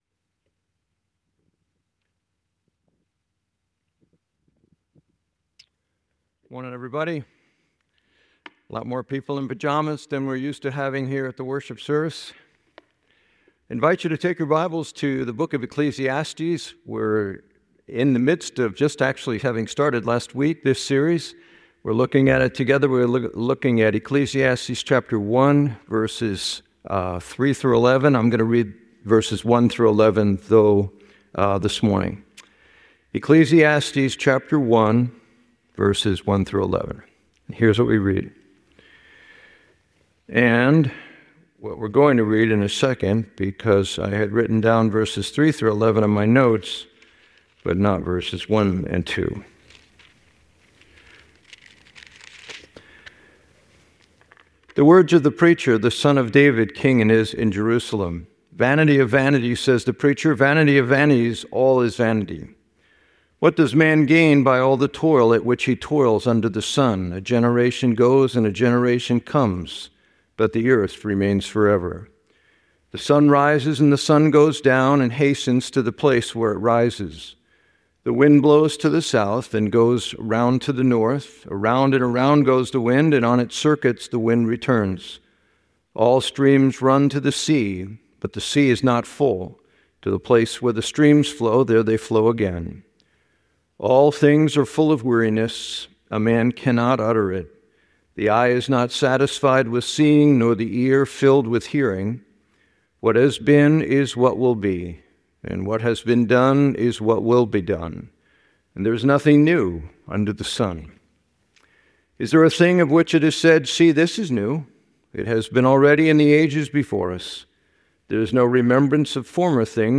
This sermon focused on Ecclesiastes 1:1-11, exploring themes of vanity, the cyclical nature of life, and the pursuit of satisfaction. It highlights three key phrases from Ecclesiastes—'all is vanity,' 'under the sun,' and 'chasing after the wind'—to emphasize the futility of seeking contentment in worldly pursuits.